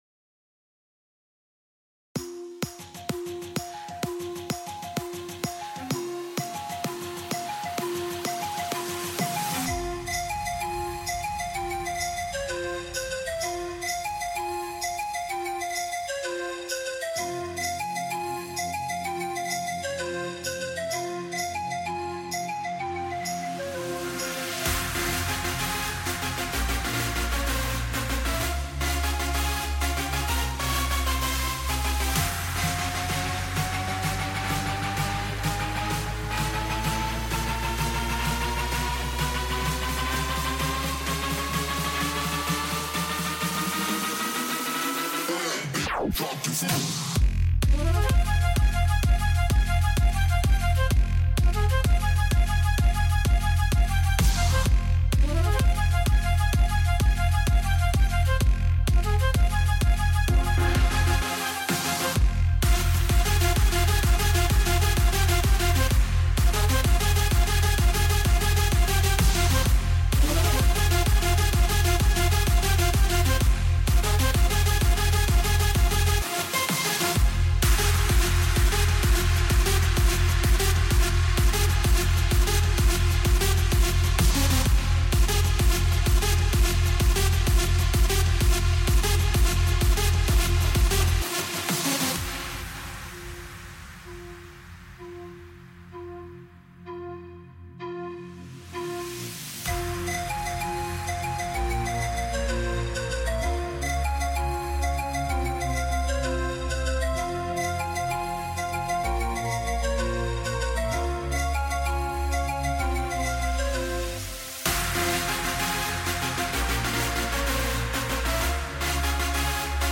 Flute - Logitrem audio
FLUTE.mp3